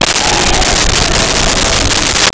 Creature Growl 07
Creature Growl 07.wav